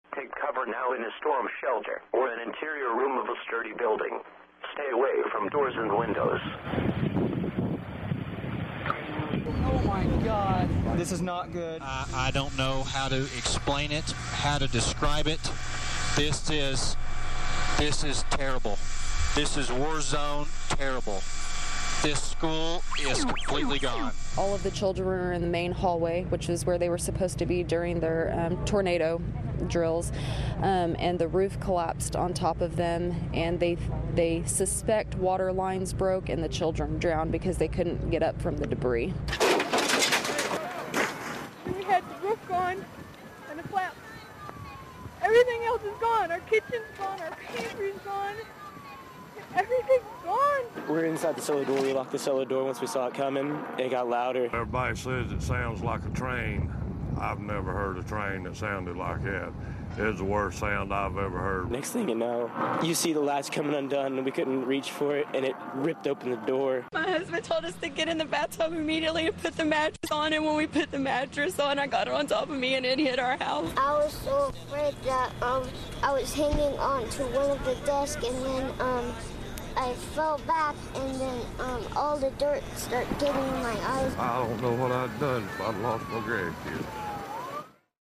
Oklahoma Tornado - In the survivors' own words